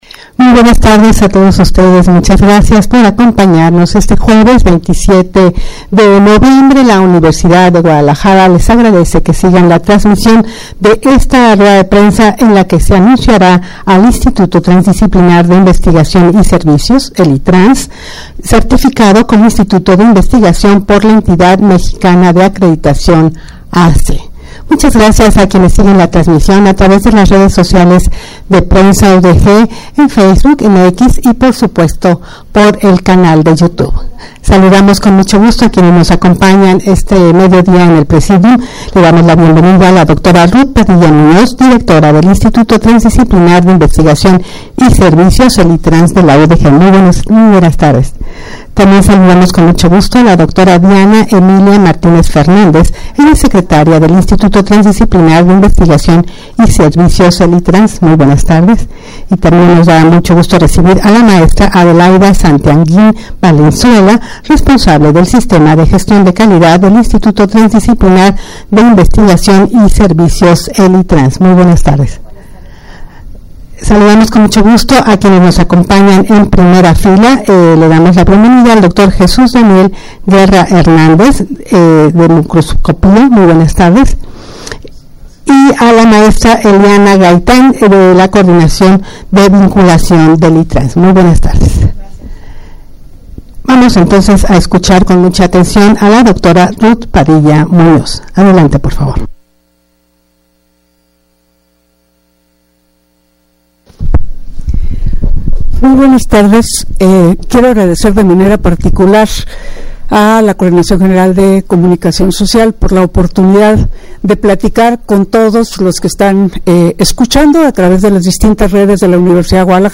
UDG Ruedas de prensa